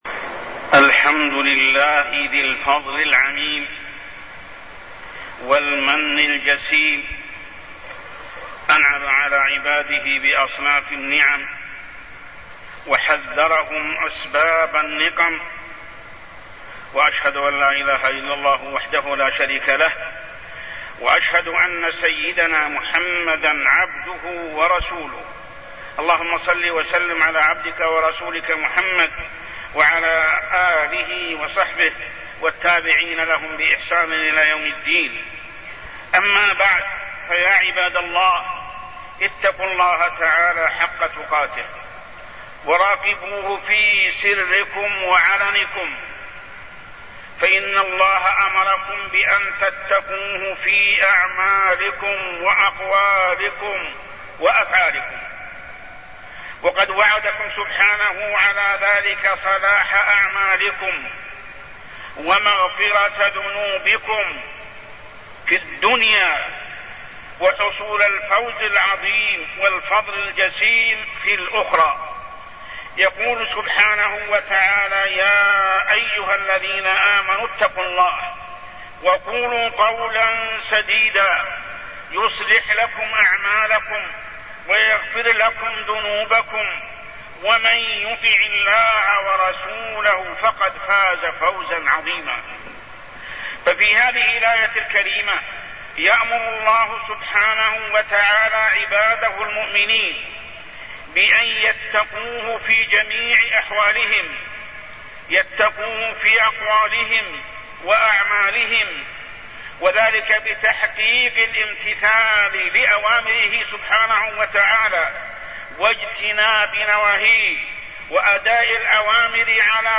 تاريخ النشر ٣٠ جمادى الأولى ١٤٢٠ هـ المكان: المسجد الحرام الشيخ: محمد بن عبد الله السبيل محمد بن عبد الله السبيل التقوى